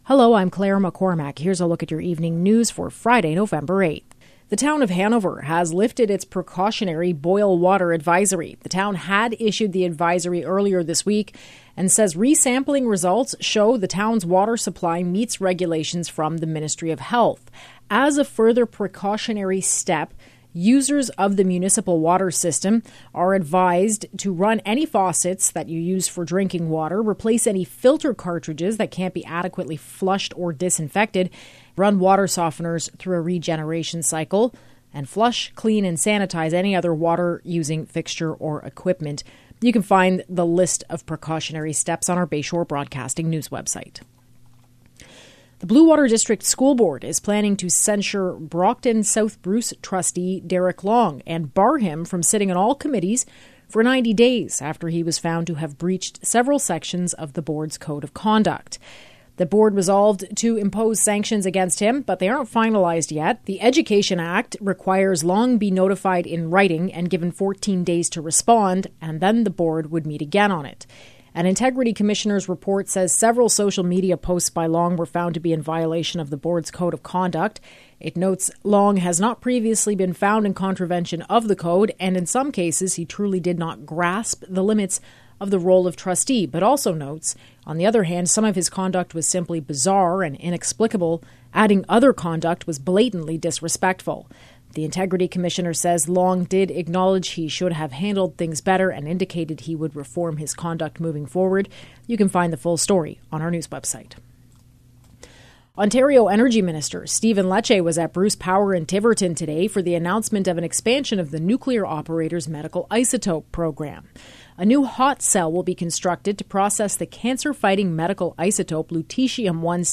Evening News – Friday, November 8